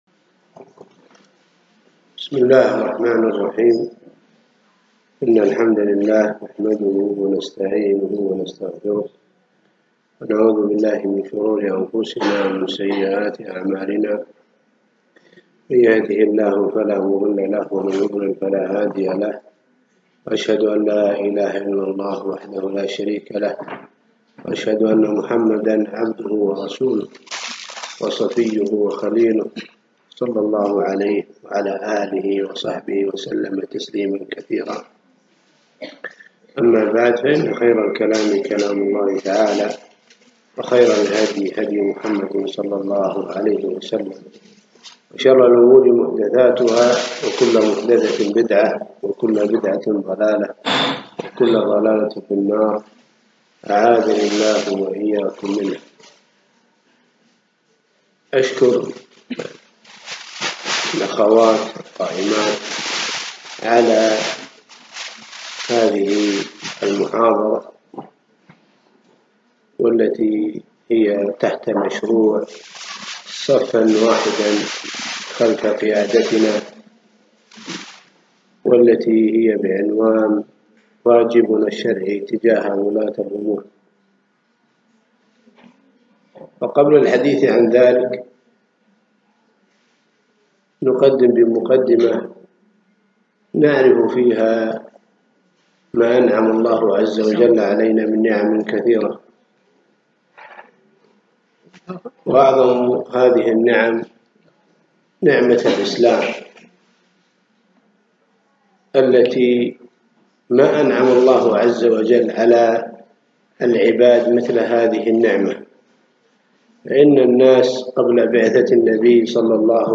محاضرة - واجبنا الشرعي تجاه ولي الأمر